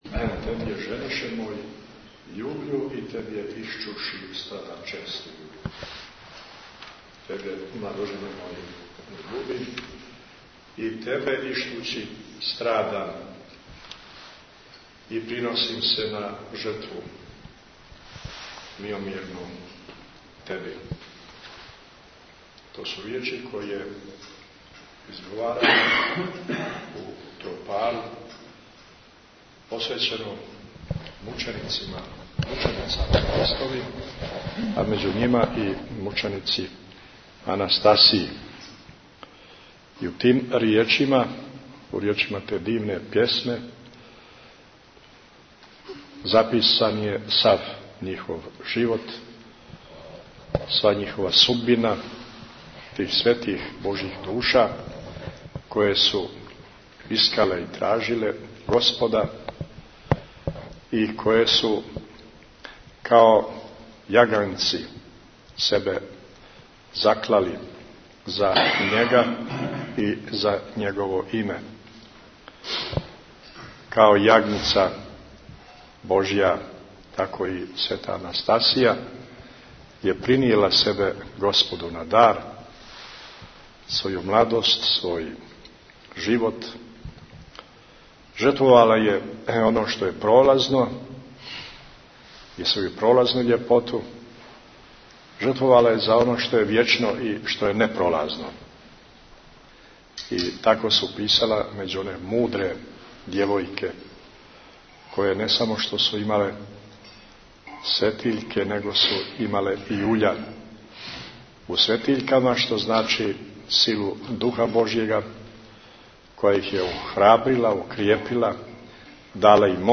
Бесједе | Радио Светигора